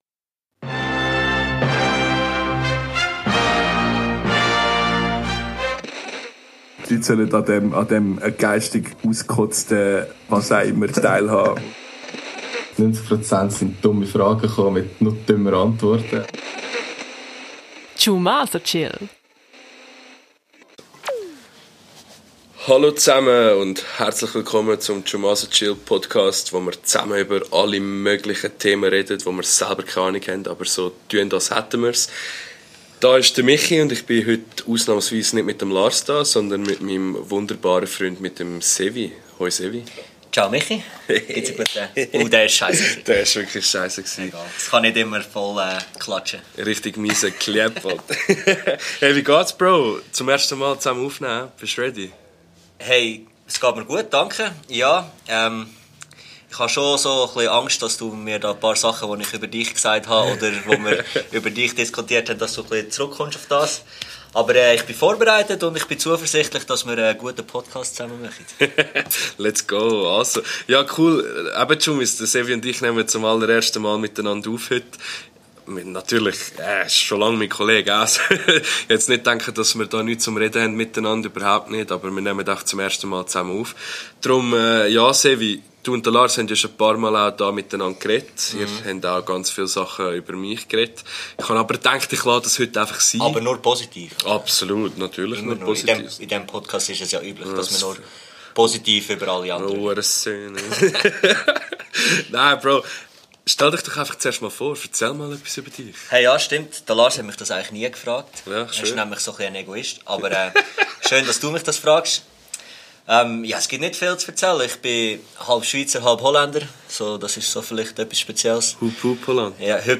zum erstemal labered die zwei direkt live für üch über sache wo eifach scho es bitzli uncool sind. ON Schueh und vieles meh heds id Liste gschafft. los ine und find use was für geistliche Schund mr die Wuche sust no für üch verpackt hend.